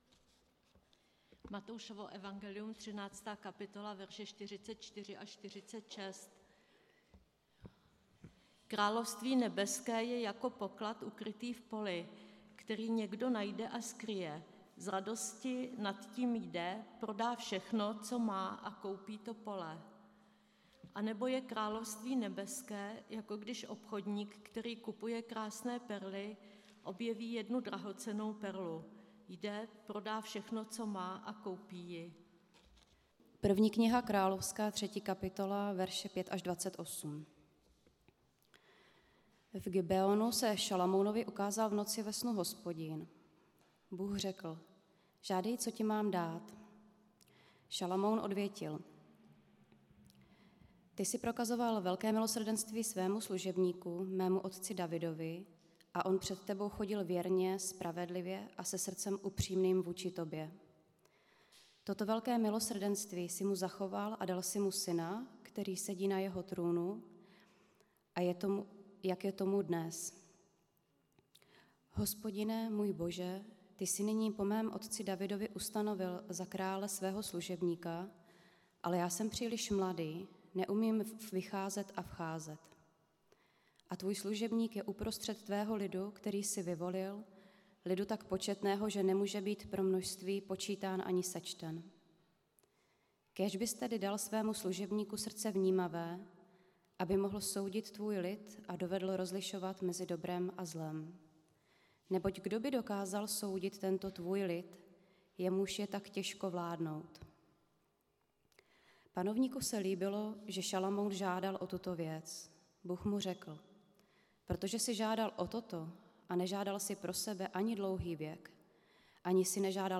Nedělní kázání – 17.7. Perla Božího království v soudu krále Šalamouna